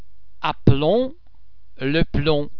BUT is silent in the word final [mb] cluster as in
b_aplomb.mp3